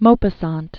(mōpə-sänt, mō-pă-säɴ), (Henri René Albert) Guy de 1850-1893.